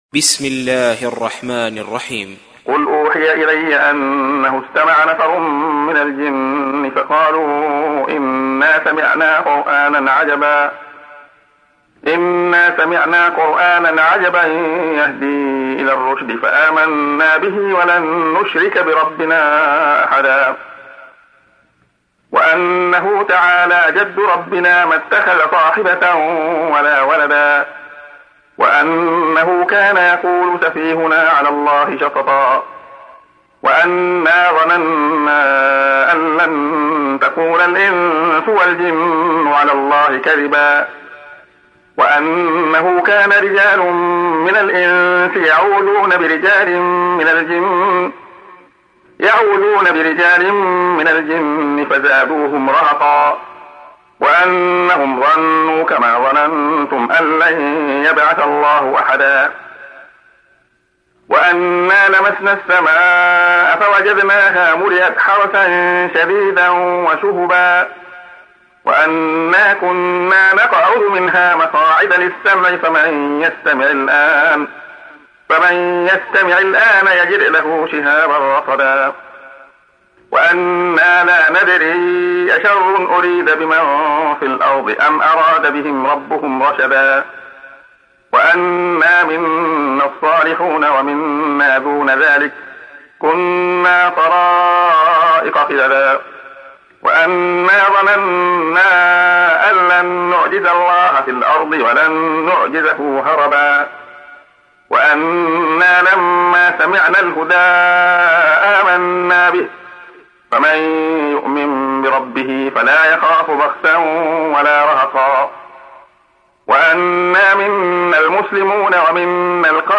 تحميل : 72. سورة الجن / القارئ عبد الله خياط / القرآن الكريم / موقع يا حسين